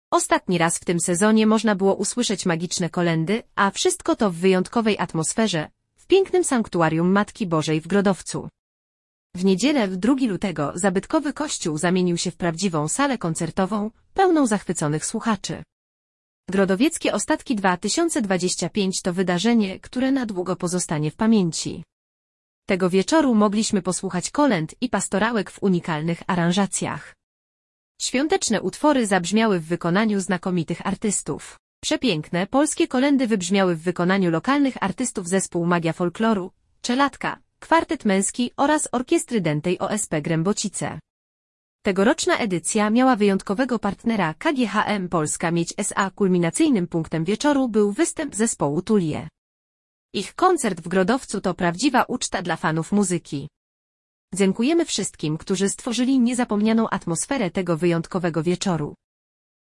W niedzielę (2.02) zabytkowy kościół zamienił się w prawdziwą salę koncertową, pełną zachwyconych słuchaczy.
Tego wieczoru mogliśmy posłuchać kolęd i pastorałek w unikalnych aranżacjach.
Przepiękne, polskie kolędy wybrzmiały w wykonaniu lokalnych artystów – zespół Magia Folkloru, Czeladka, Kwartet Męski oraz Orkiestry Dętej OSP Grębocice.